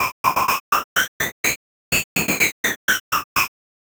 Synthetic 01.wav